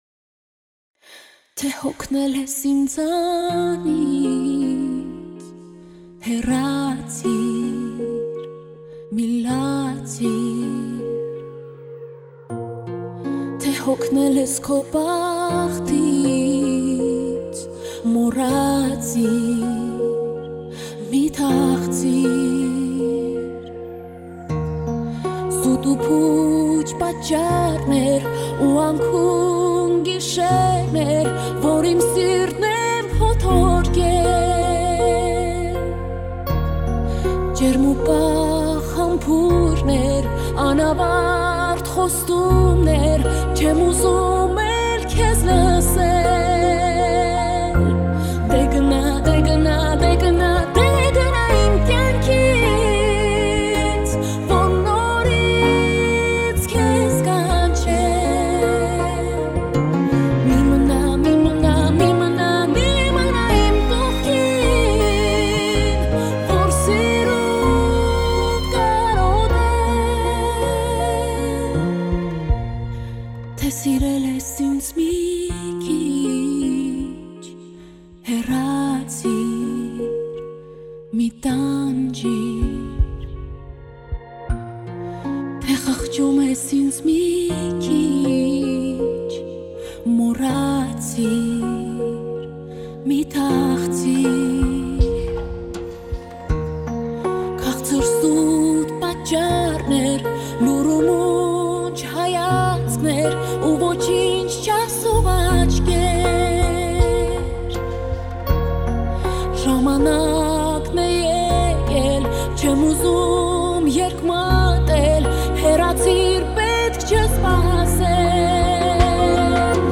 Հայկական երգ